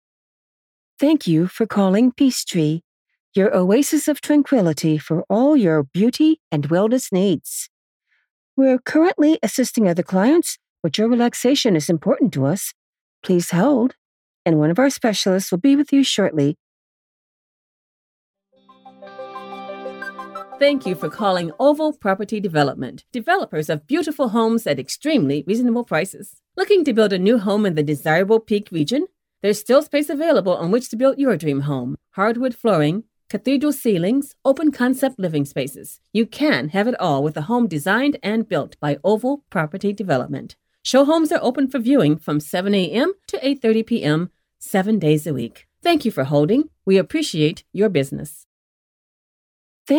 Female
Adult (30-50), Older Sound (50+)
Phone Greetings / On Hold
Calm Tone & An Energetic Mood